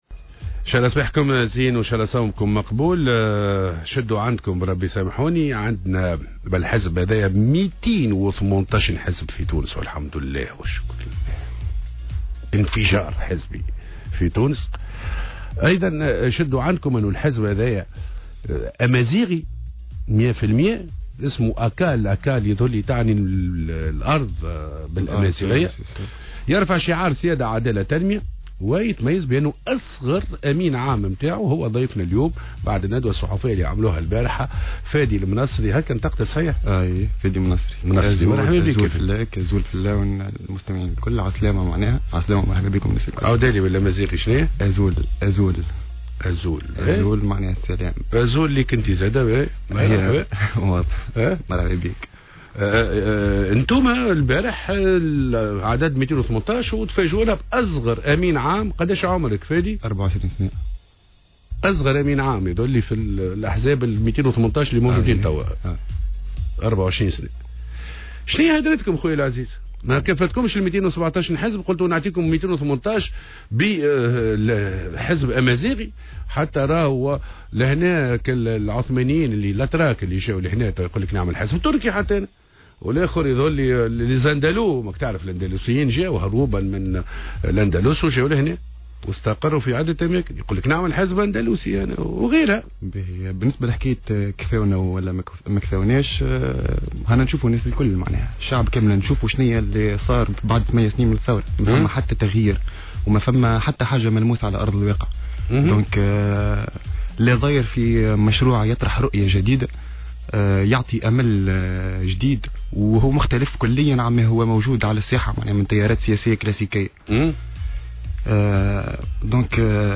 ضيف صباح الورد